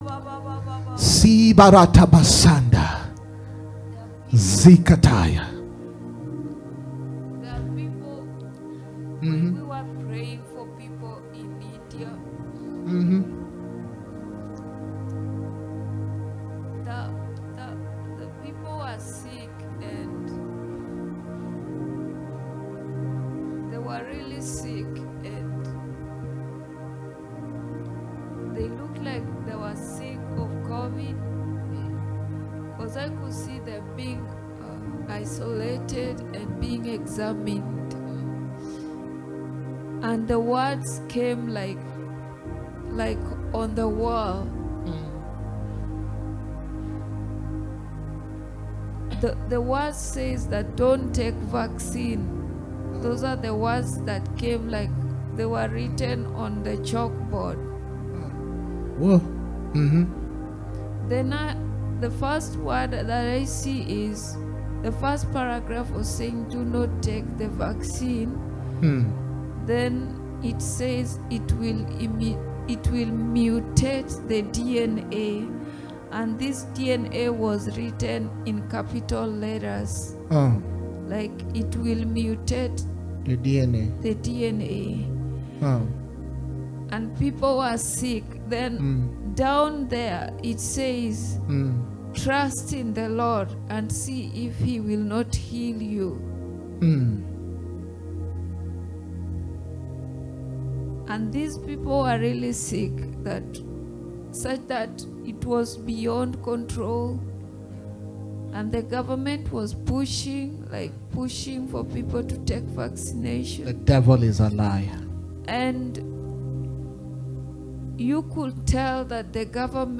While praying for the nation of India last Friday on our Healing and Deliverance Service, the Lord brought a Word concerning the ongoing situation.